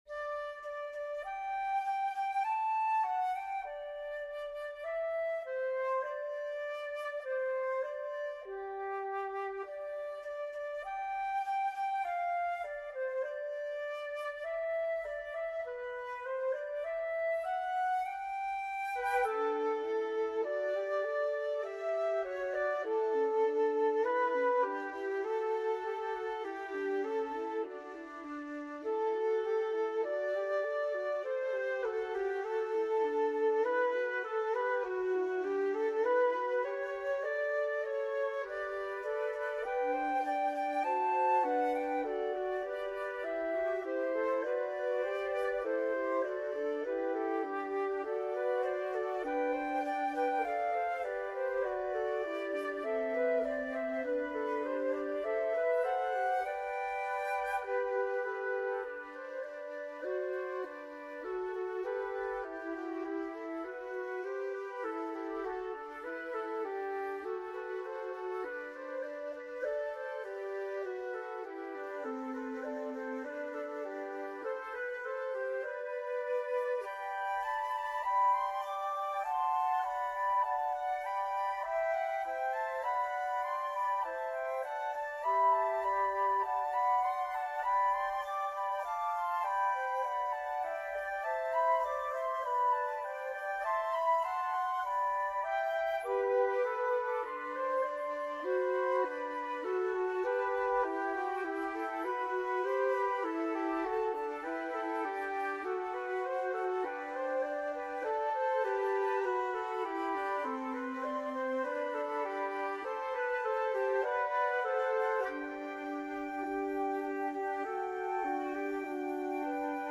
Mini Fugue Three Flutes